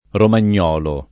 romagnolo [ roman’n’ 0 lo ]